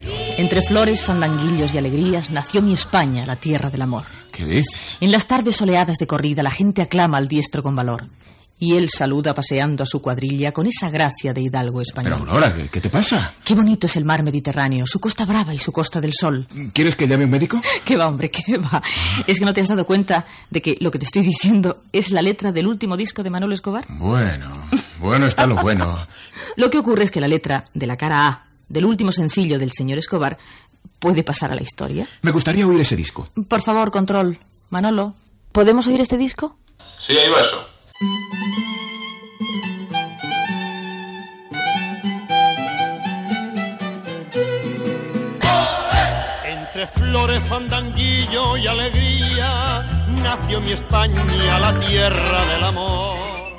Entreteniment
Fragment extret del programa "Audios para recordar" de Radio 5 emès el 8 de febrer del 2013.